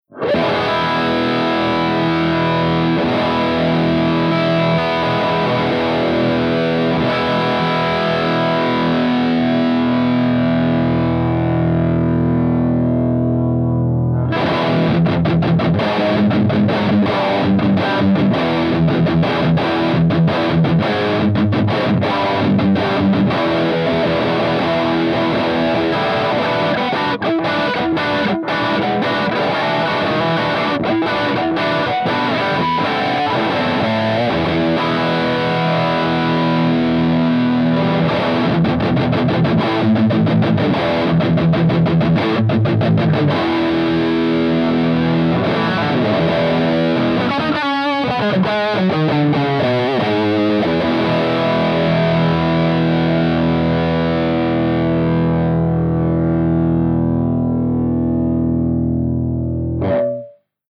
142_ROCKERVERB_CH2HIGHGAIN_V30_HB